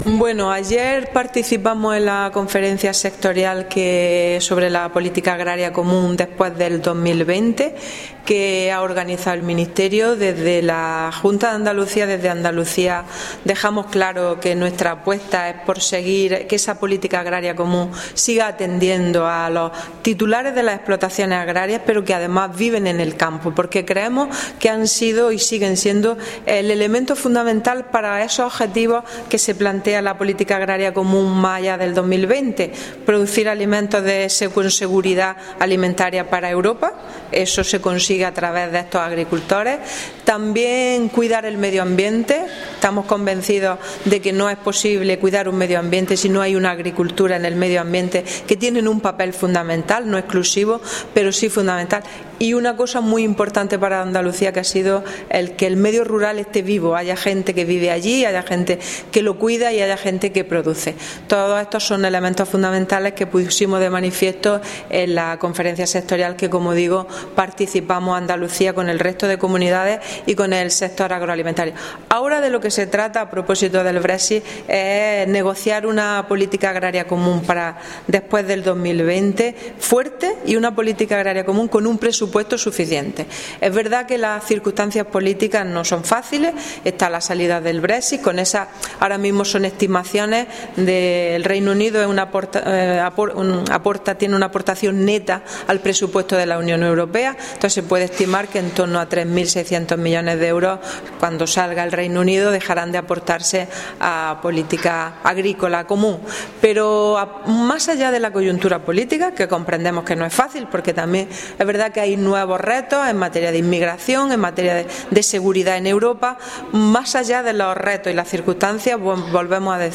Declaraciones consejera PAC post 2020